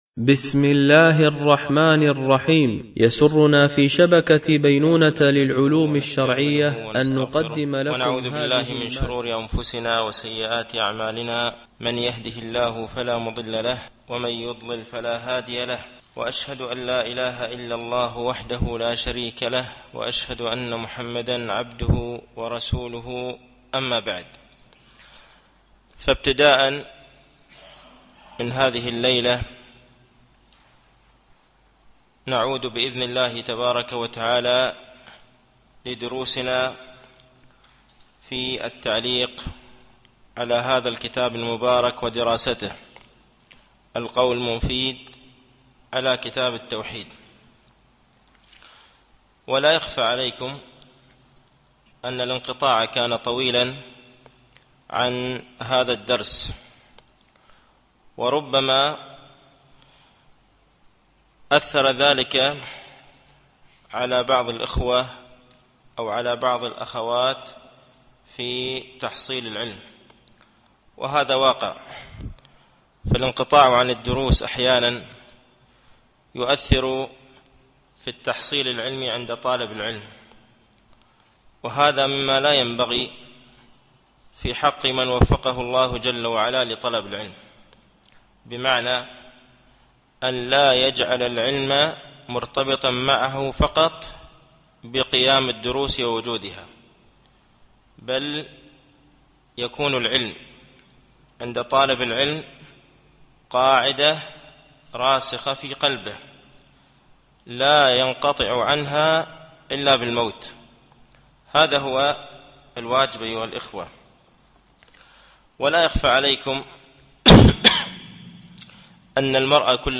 التعليق على القول المفيد على كتاب التوحيد ـ الدرس الثالث و العشرون بعد المئة